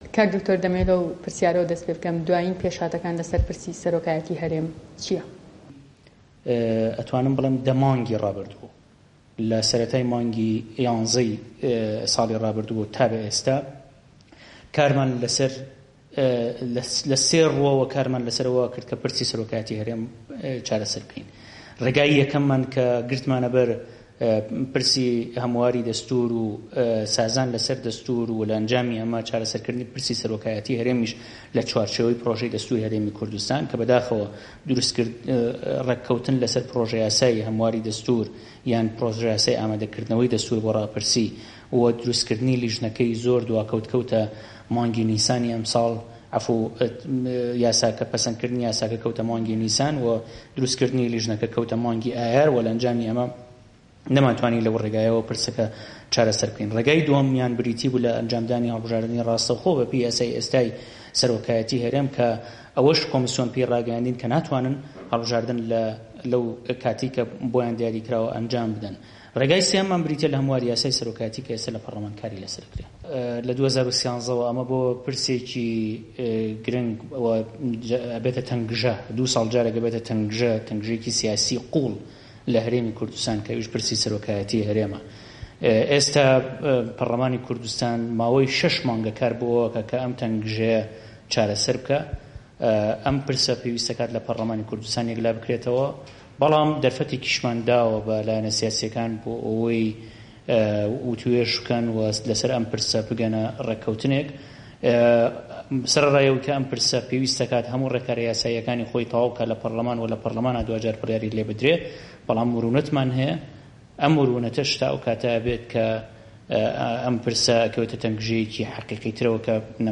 Interview with Dr. Yousif Muhammad